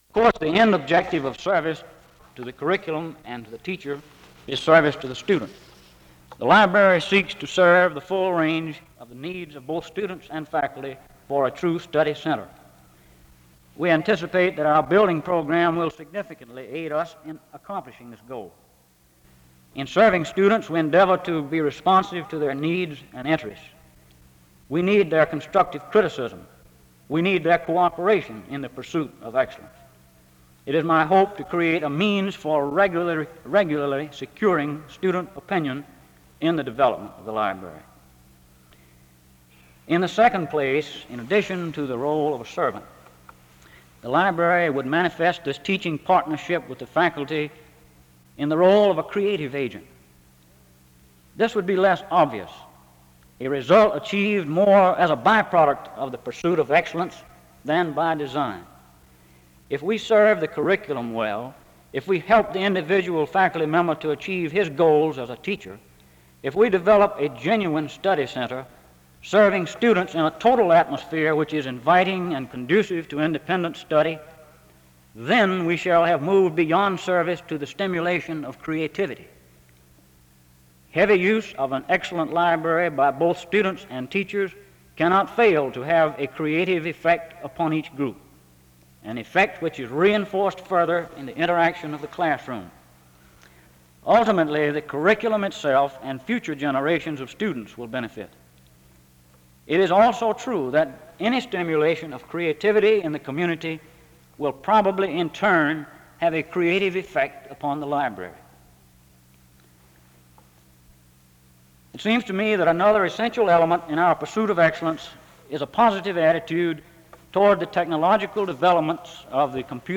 The service opens in a word of prayer from 0:00-0:48. An introduction to the speaker is given from 0:58-3:20.
SEBTS Chapel and Special Event Recordings